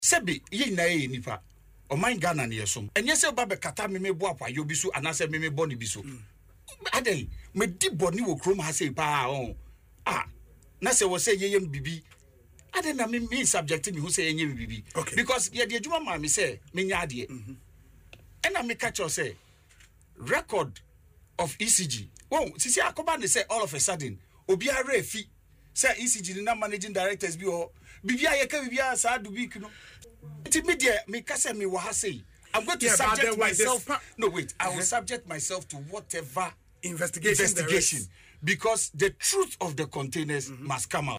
Mr. Mahama disclosed this in an interview on Asempa FM’s Ekosii Sen, denying any wrongdoing during his tenure.